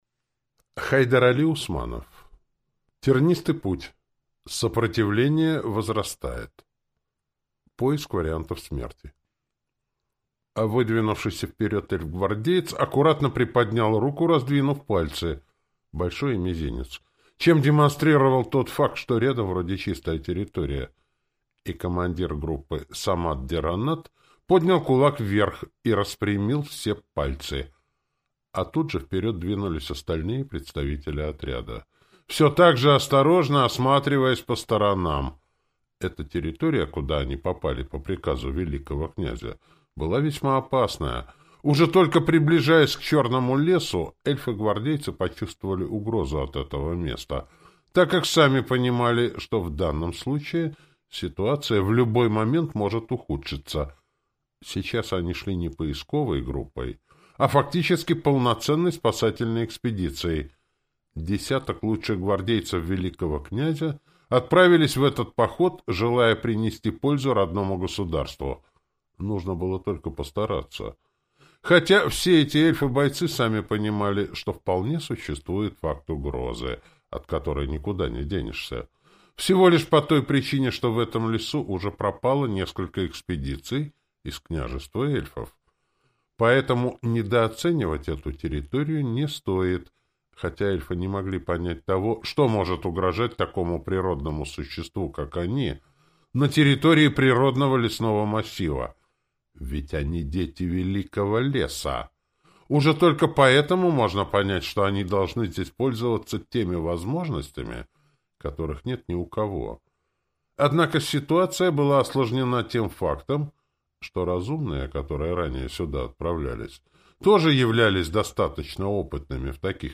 Аудиокнига.